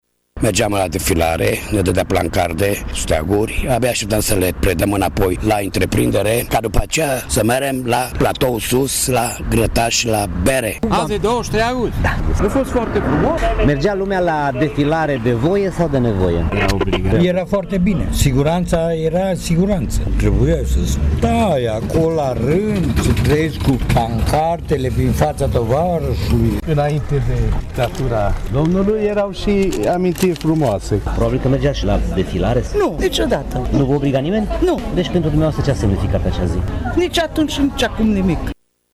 Unii târgumureșeni susțin că ziua reprezenta un simbol al unei stabilități sociale care acum nu mai există. Altora nu le-a păsat și nici nu le pasă de semnificația acestei zile: